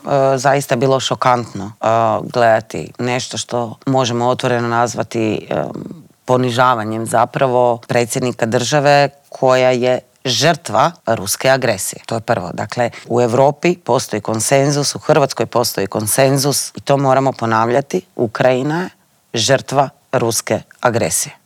O političkim aktualnostima, nadolazećim lokalnim izborima te o aktualnoj geopolitičkoj situaciji u svijetu razgovarali smo u Intervjuu tjedna Media servisa sa saborskom zastupnicom i koordinatoricom stranke Možemo! Sandrom Benčić.